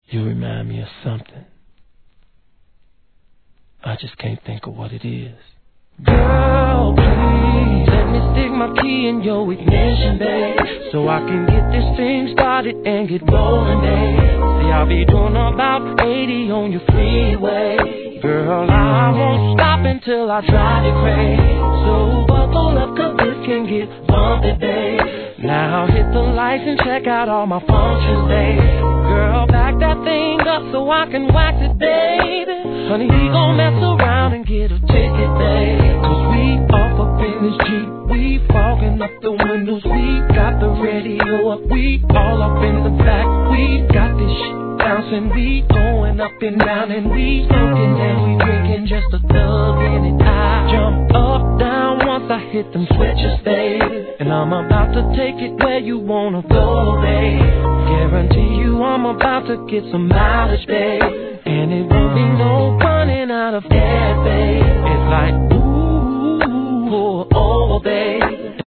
HIP HOP/R&B
メロウかつグルービーな傑作で